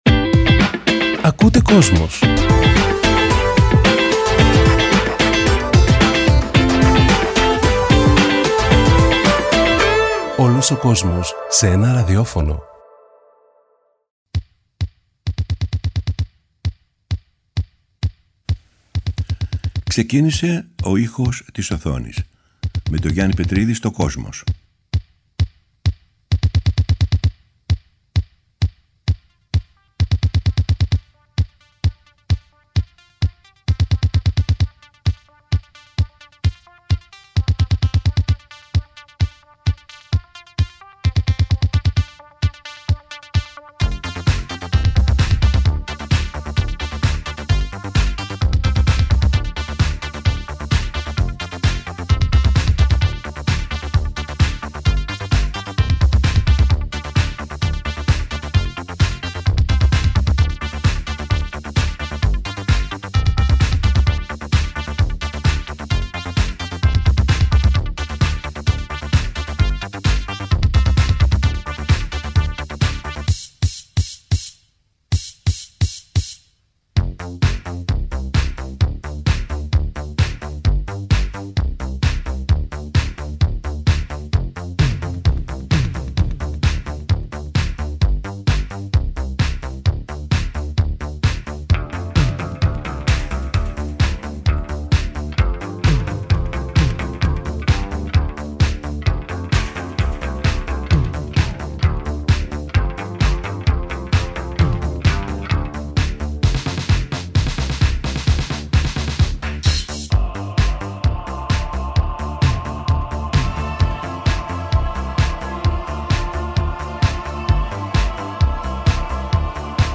SOUNDTRACKS
Κινηματογραφικη Μουσικη